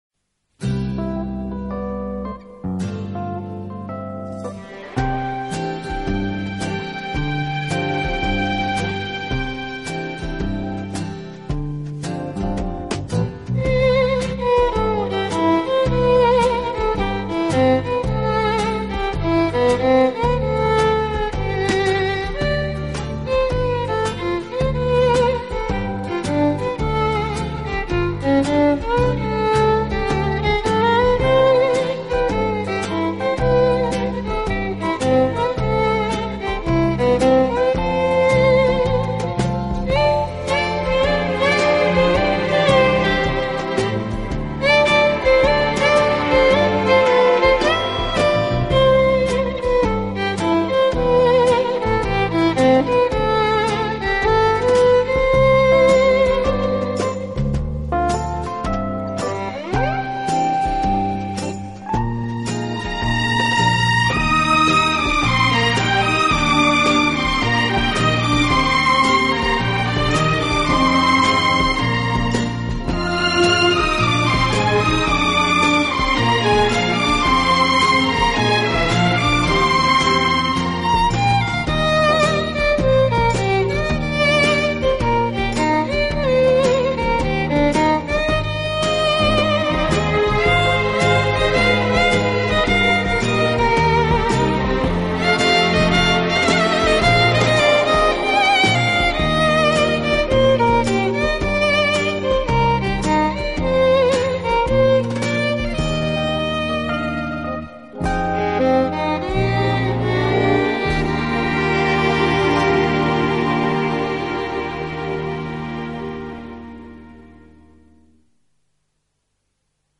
Genre: International ~ World
Studio/Live: Studio
张CD中您可以明显感受到乐队无论演奏流行音乐、民歌名谣还是古典音乐，都有一股子爵士的韵味。